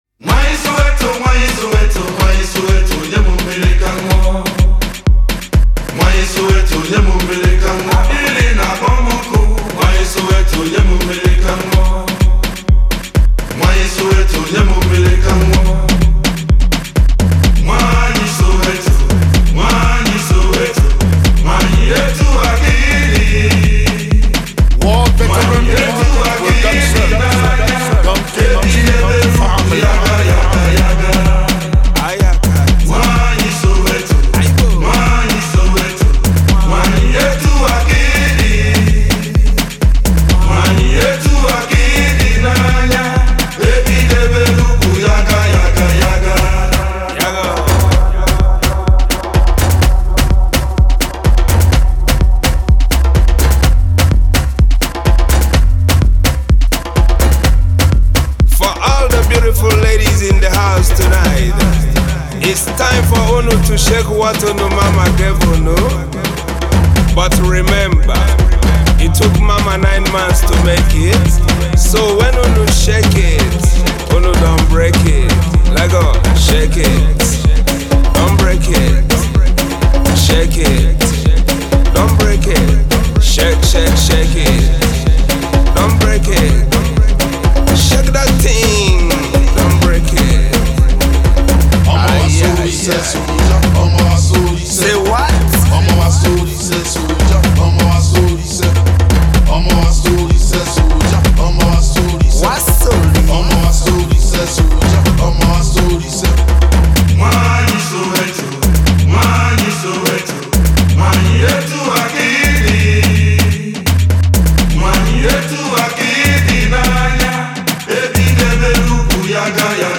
Igbo Music, Pop